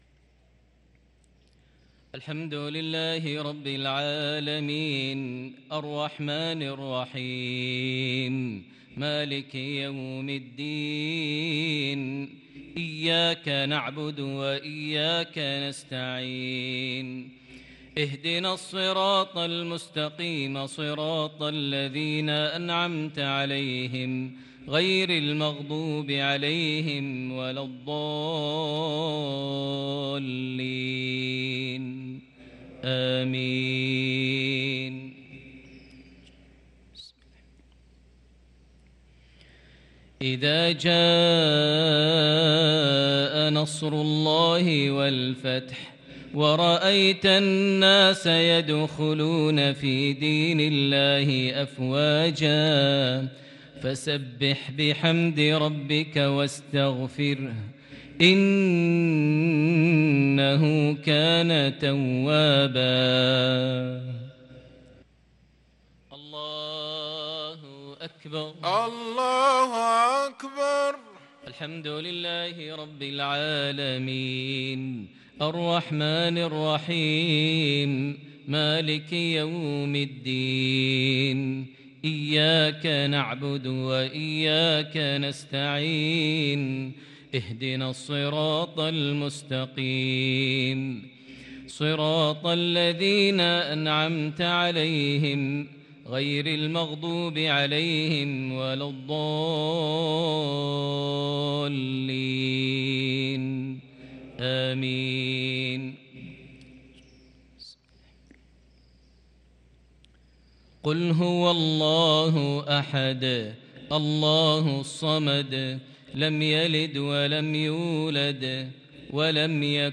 صلاة المغرب للقارئ ماهر المعيقلي 23 شعبان 1443 هـ
تِلَاوَات الْحَرَمَيْن .